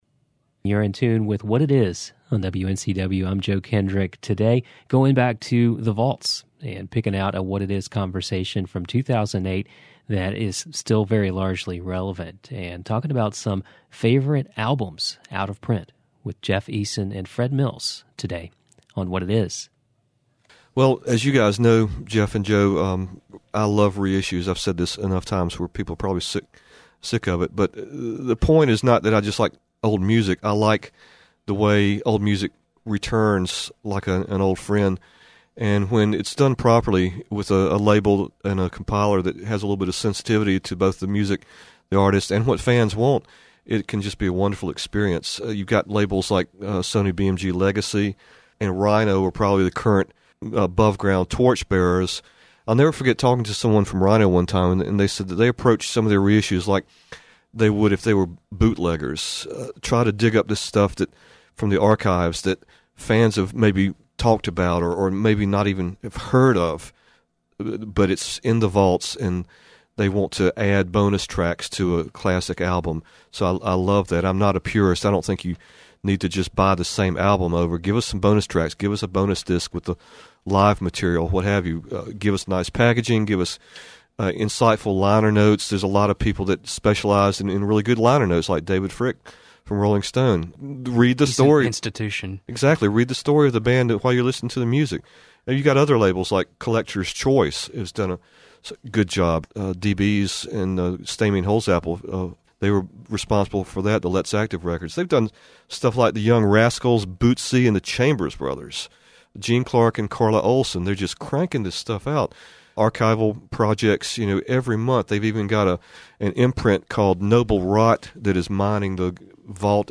a 2008 conversation
The podcast is the full version of what was abridged for broadcast on air.